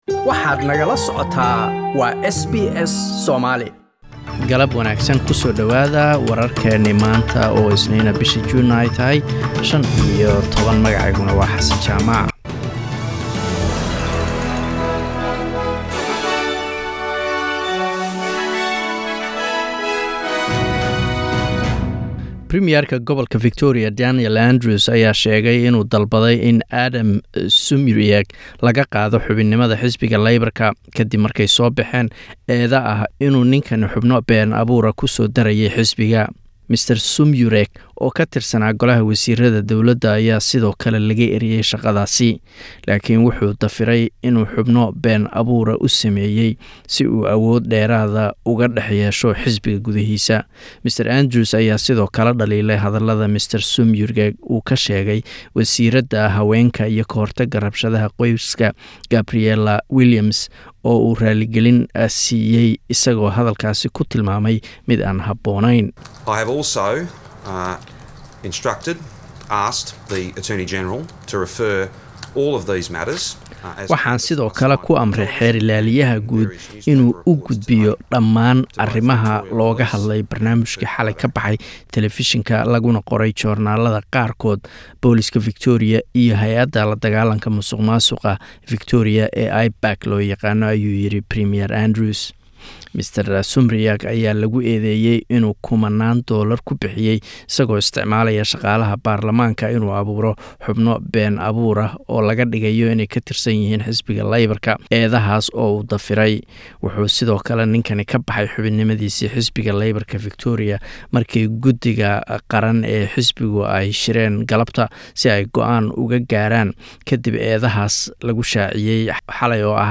Wararka SBS Somali Maanta Isniin 15 June